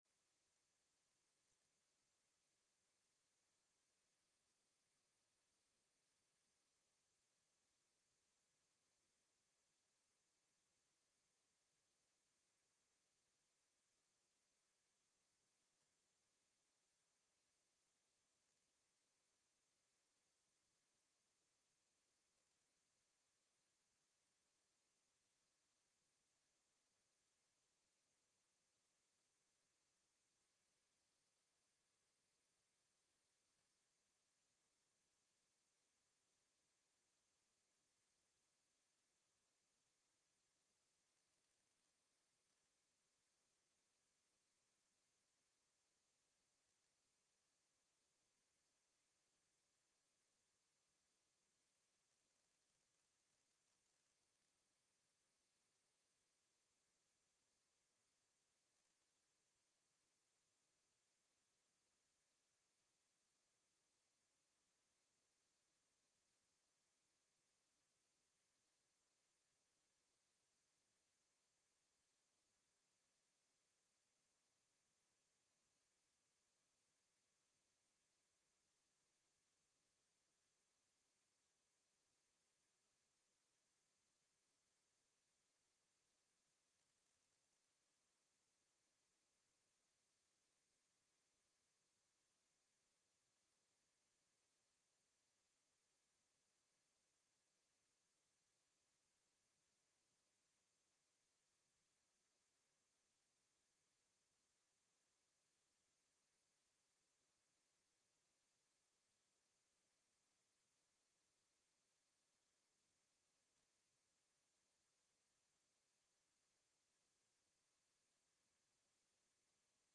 Sessão plenária do dia 26/10/15